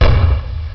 Звук взрыва гранаты в Quake